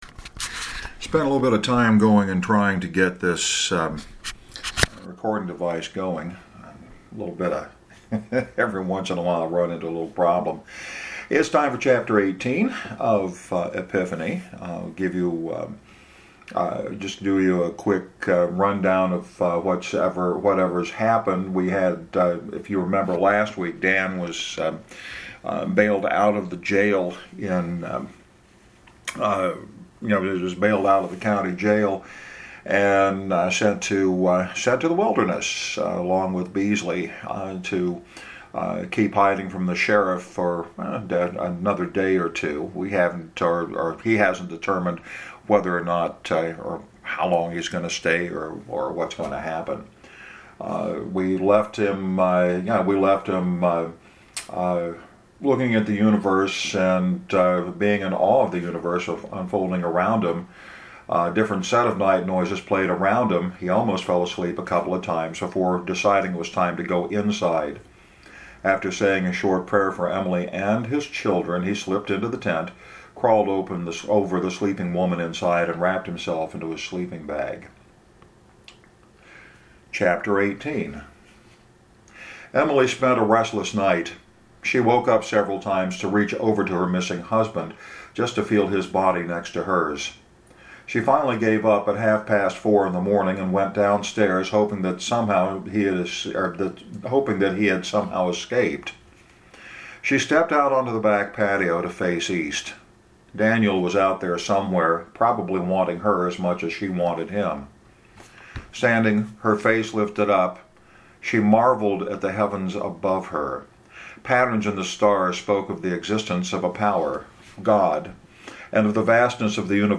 A little bit rough this week. I stumble in a place or two. Emily misses Dan while life goes on.